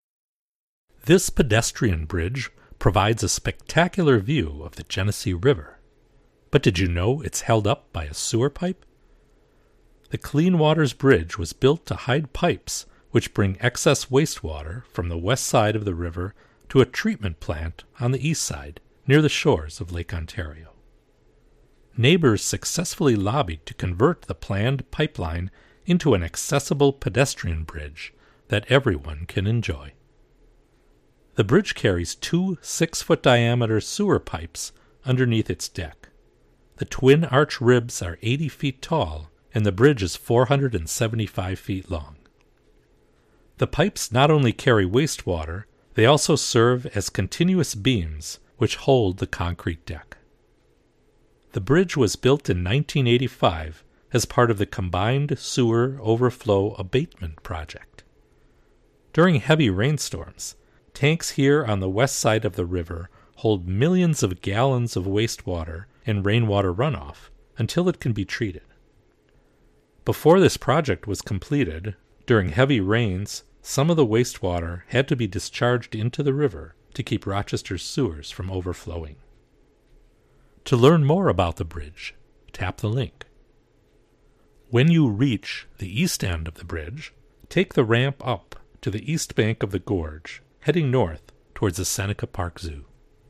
This narrated walking tour is arranged in two loops, which begin and end at Upper Maplewood Park.  Following the first loop, you’ll enjoy wonderful views of the Genesee River as you cross a pedestrian bridge and return over the Veterans Memorial Bridge.